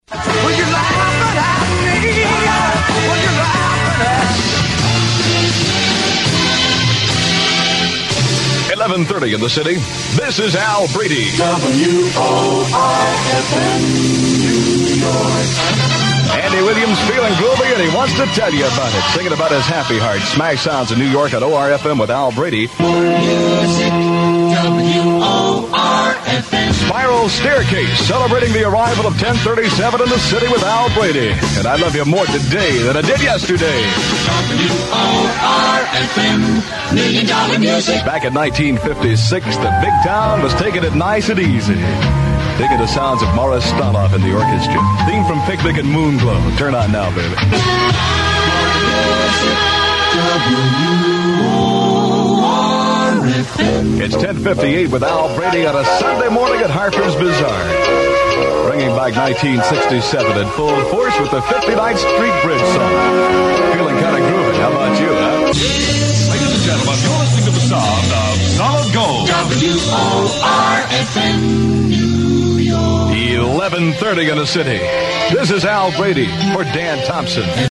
They range in quality from outstanding to fair.
Here are short airchecks of some of the voices heard on WOR-FM during those years